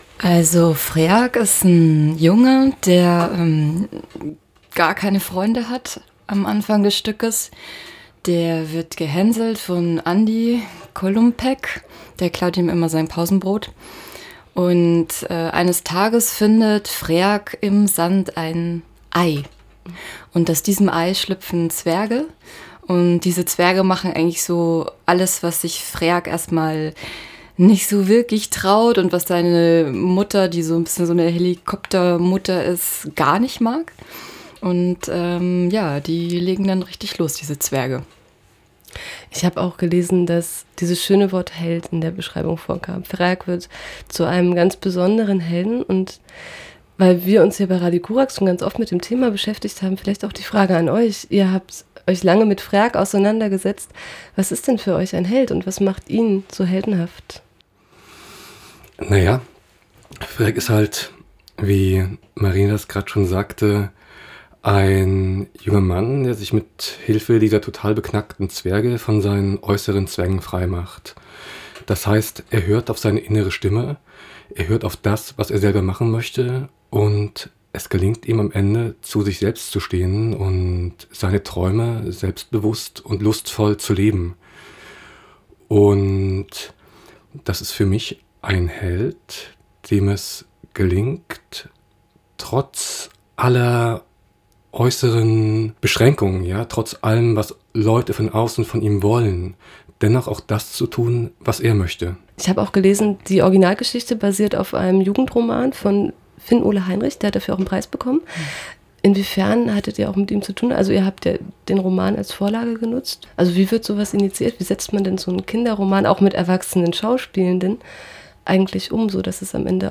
Dies wird ab dem 29. November in Halle aufgeführt. Begleitet wurde das Stück durch die musikalische Untermalung der SchauspielerInnen.